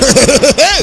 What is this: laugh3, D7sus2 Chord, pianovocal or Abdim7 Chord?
laugh3